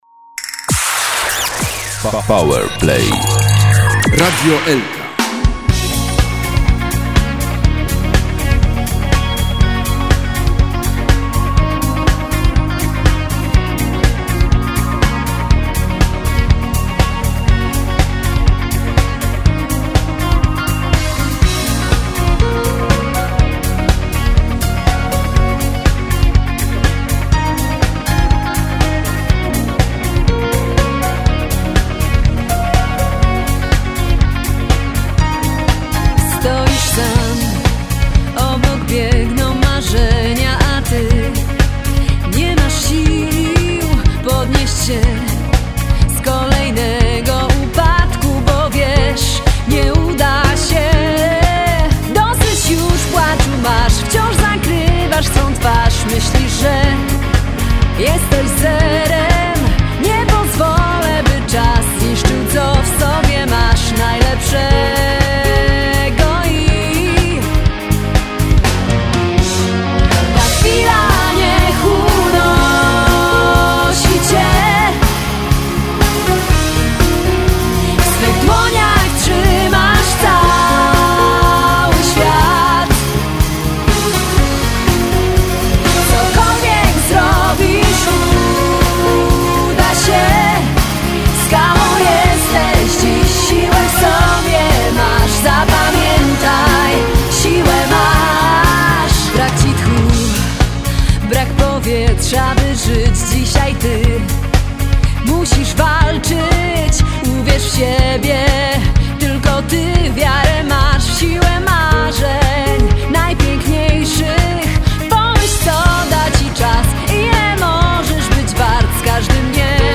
instr. klawiszowe
wokalistka
gitary, śpiew
gitara basowa
perkusja